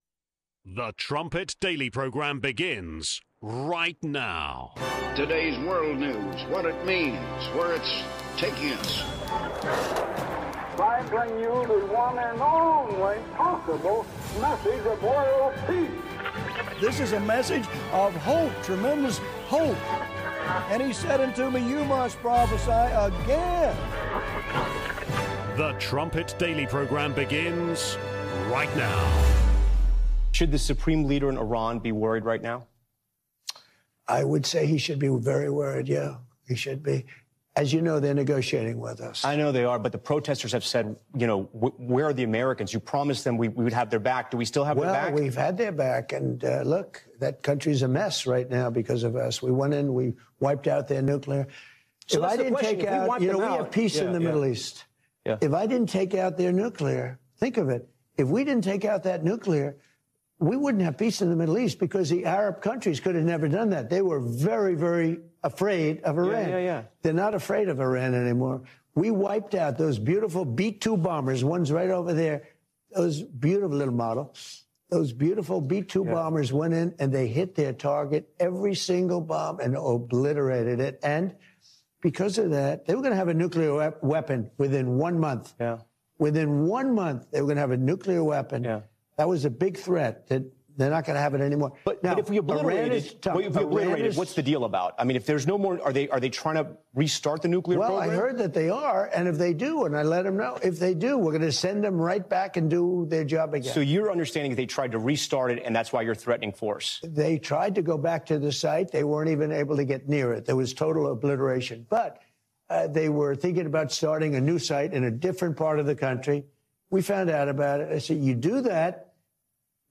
18:00 Trumpet Daily Interview